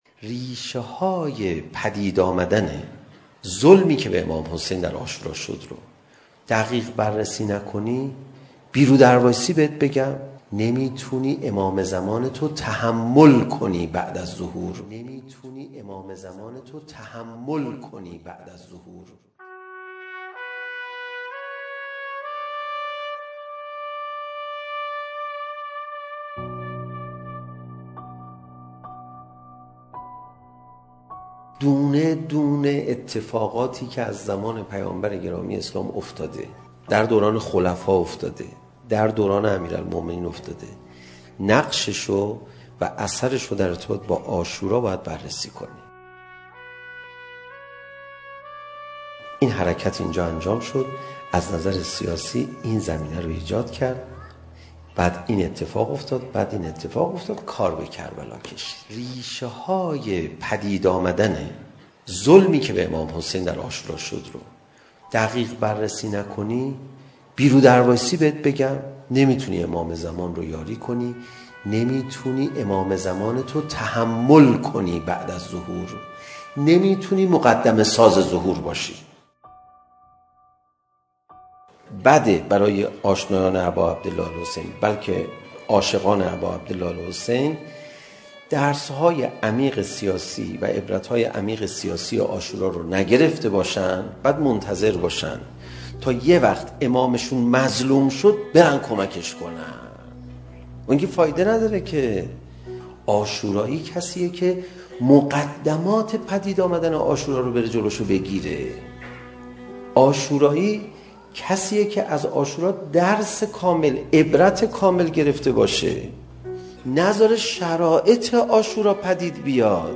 بیانات استاد علیرضا پناهیان با موضوع "ریشه یابی عاشورا"
سخنرانی